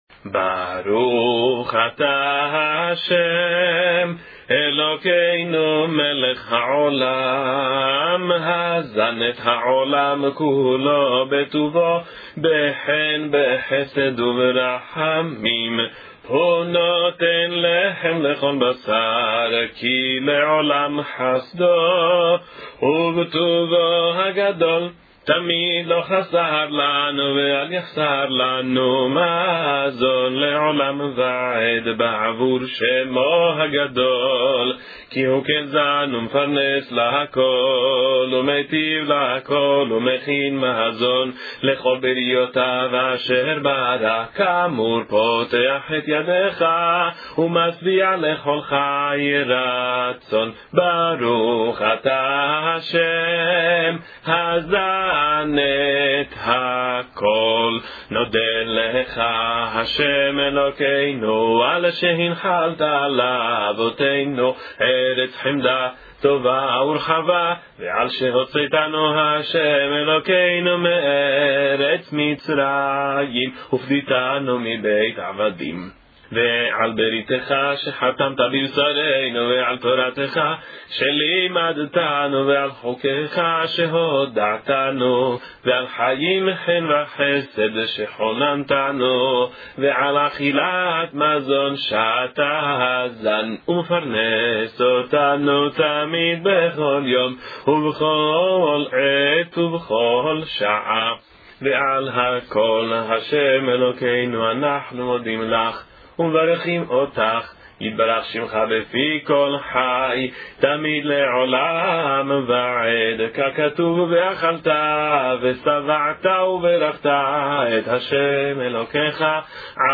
Por isso, gravei sempre com “Hashem” (Ad-onai), “Elokênu” (E-lohênu) e “Kel” (E-l).
3) No lugar que se fala “Harachaman” pela família e/ou anfitrião, baixei um pouco a voz – deixando “livre” para cada situação.
Bircat-Hamazon-Cantado.mp3